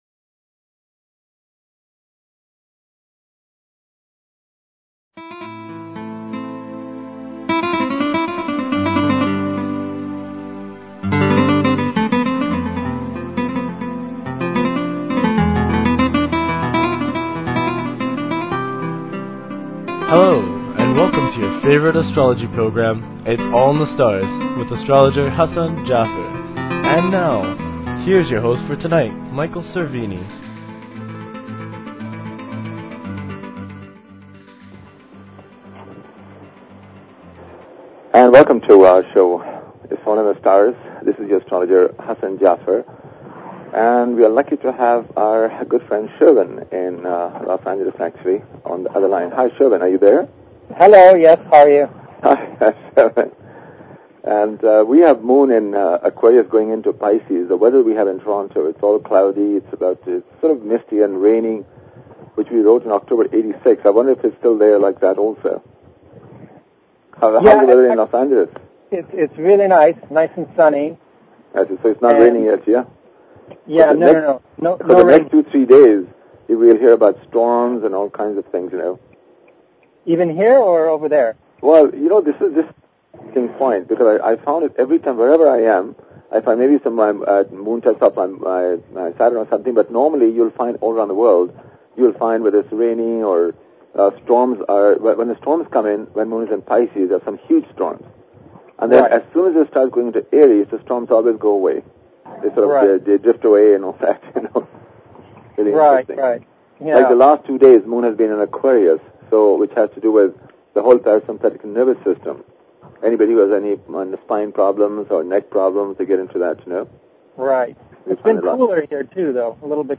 Talk Show Episode, Audio Podcast, Its_all_in_the_Stars and Courtesy of BBS Radio on , show guests , about , categorized as